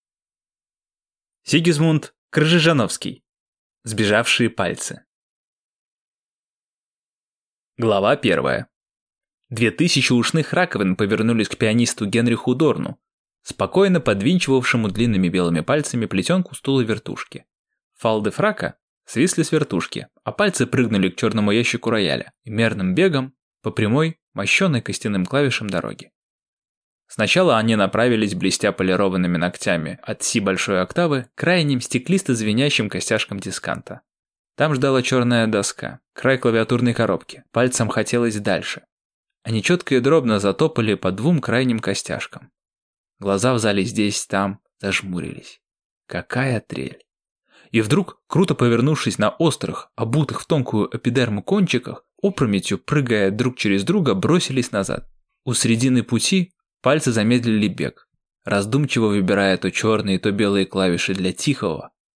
Аудиокнига Сбежавшие пальцы | Библиотека аудиокниг